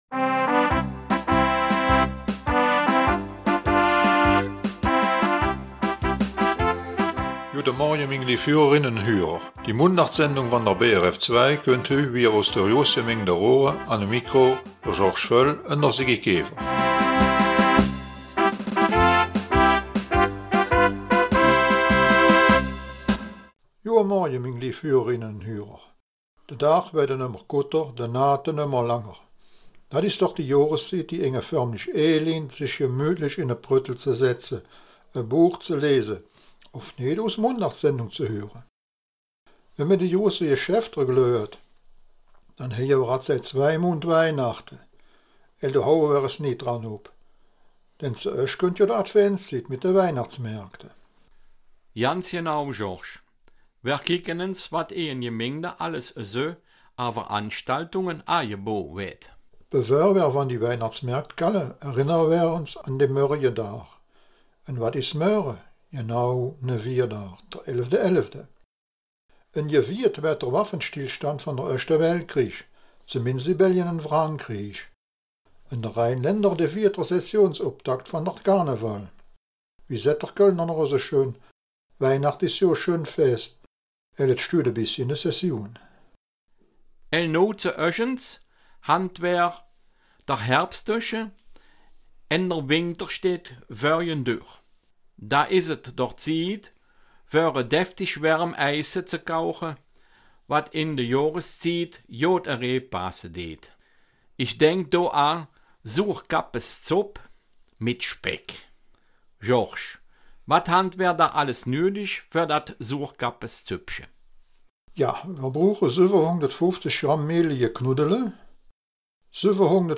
Raerener Mundart - 10. November